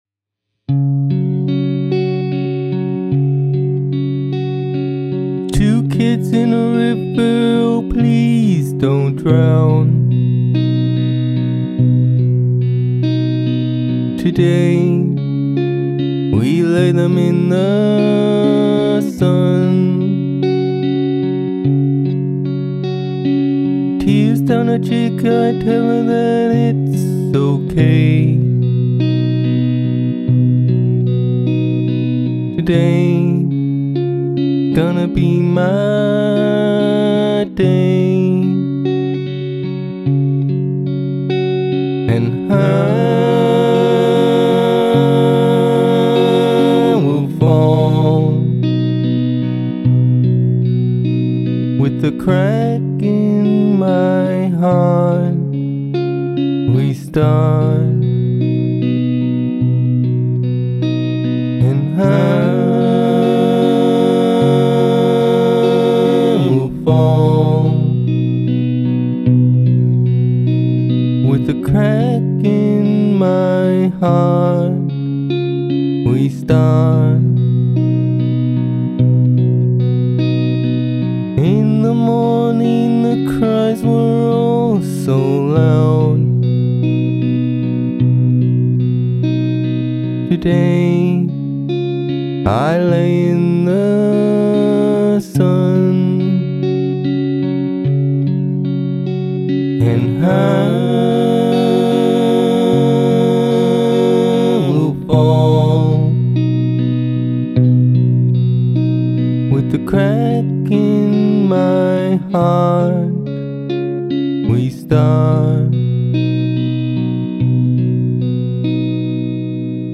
Sad little tune I wrote. The harmonies arent working.
Here is a raw one, one with some FX, and one with a low-fi thing on the vocals.